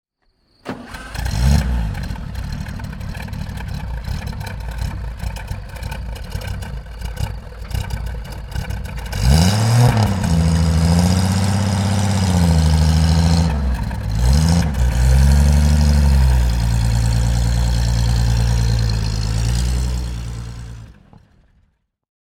Fiat 1500 Berlina (1936) - Starten und Leerlauf